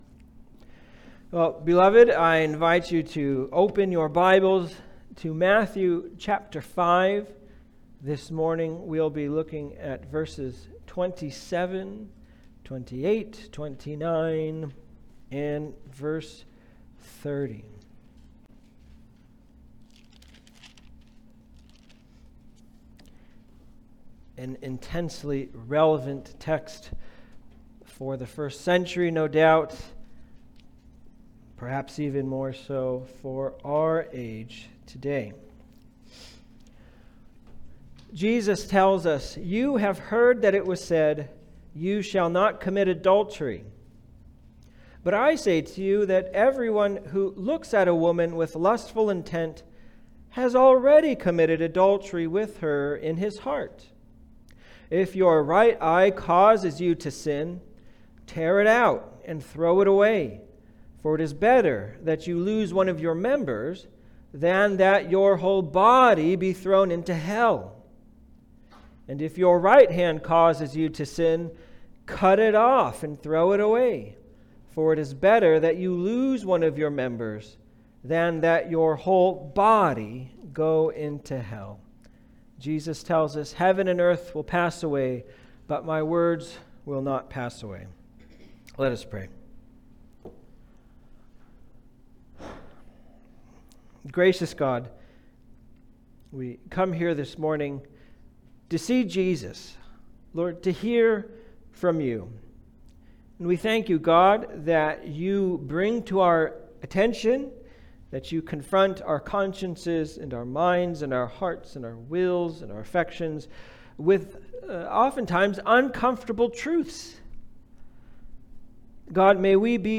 Passage: Matthew 5:27-30 Service Type: Sunday Service